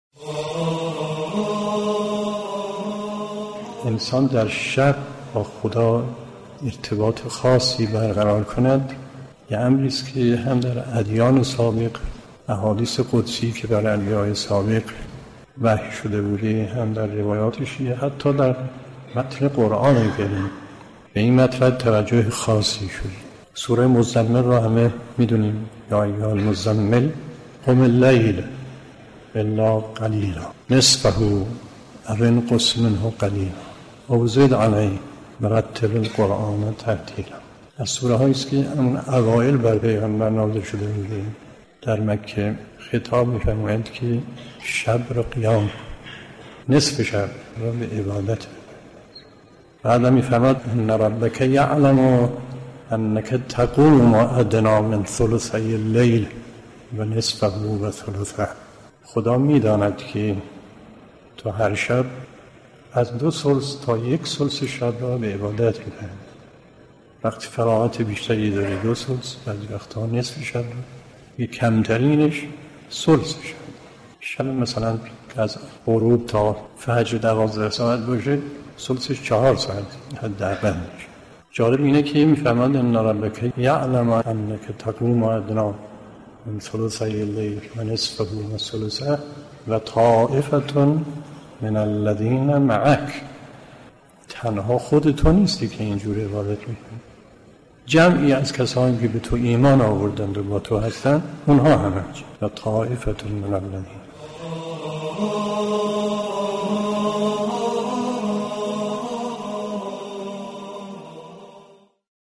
در برنامه «شرح و توضیح تلاوت» از گروه علوم قرآنی رادیو قرآن، آیات 1 تا 4 و 20 سوره مباركه مزمل با كارشناسی آیت‌الله مرحوم محمدتقی مصباح یزدی تفسیر شد. ایشان در این گفتار به اهمیت قیام شب و جایگاه عبادت شبانه در قرآن كریم، روایات شیعه و ادیان پیشین پرداختند.